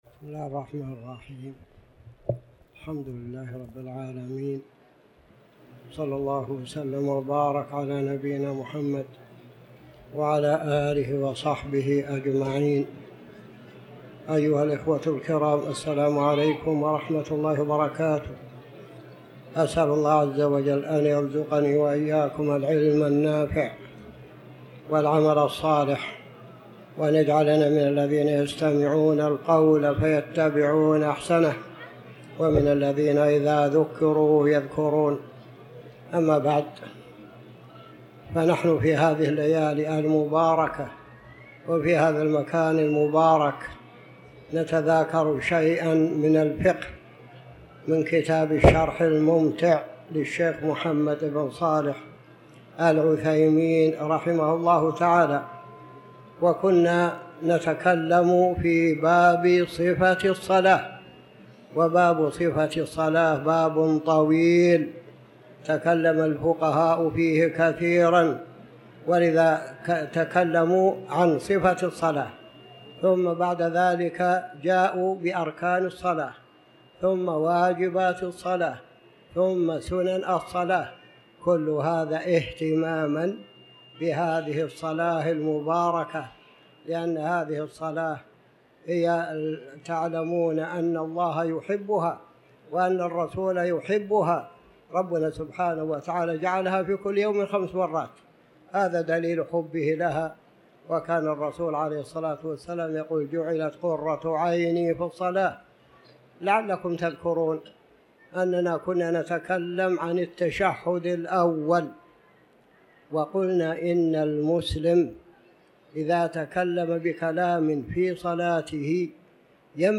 تاريخ النشر ١٤ ذو القعدة ١٤٤٠ هـ المكان: المسجد الحرام الشيخ